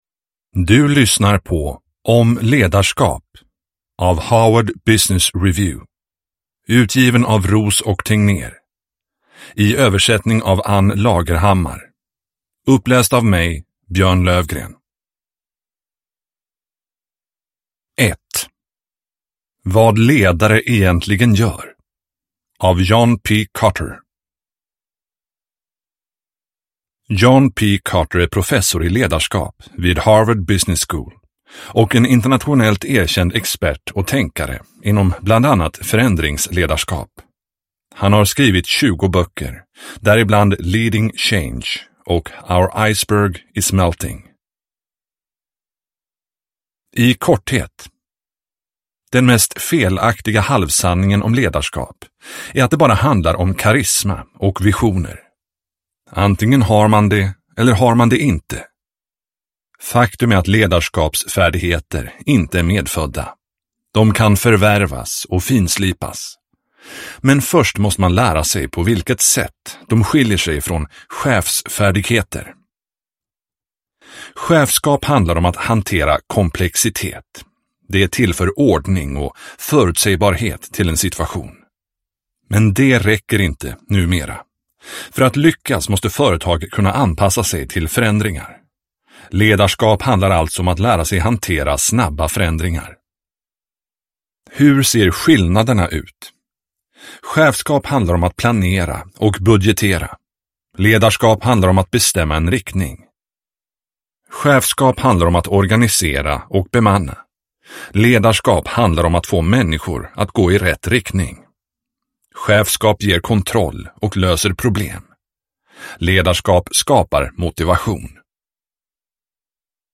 Harvard Business Review: om ledarskap – Ljudbok – Laddas ner